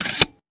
weapon_foley_pickup_18.wav